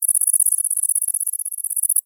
INSECT_Crickets_mono.wav